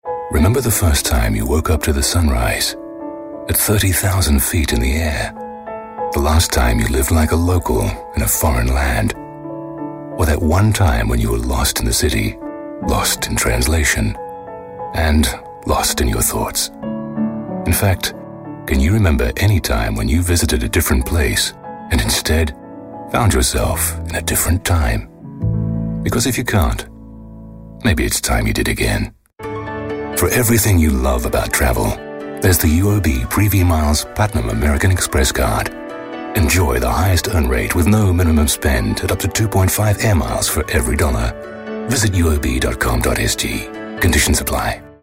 Not American but not quite British either, I have a neutral accent that has been well received in continental Europe precisely because it is neither.
English - Transatlantic
Middle Aged